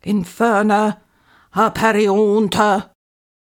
mandrake foundry13data/Data/modules/psfx/library/incantations/older-female/fire-spells/inferna-aperiuntur
inferna-aperiuntur-commanding.ogg